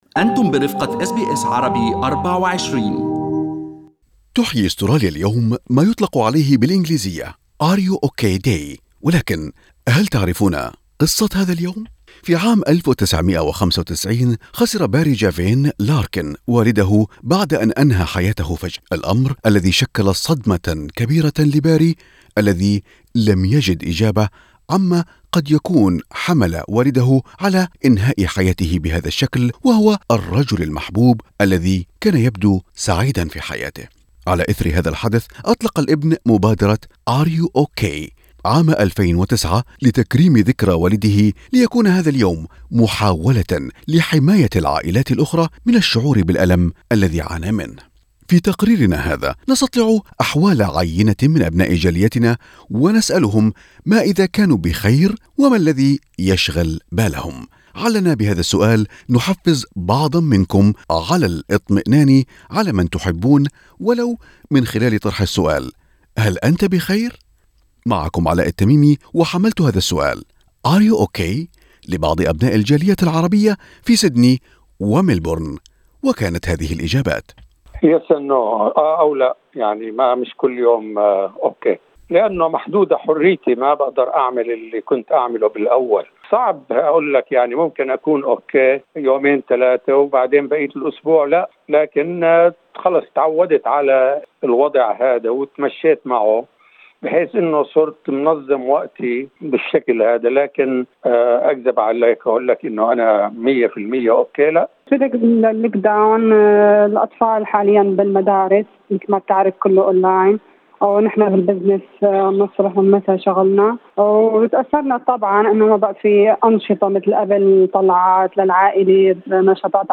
أجرت أس بي أس عربي24 تقريراً بمناسبة هذا اليوم، استطلعت فيه أحوال عيّنة من أبناء الجالية في سيدني وملبورن مستفسرة إذا كانوا بخير وما الذي يشغل بالهم؟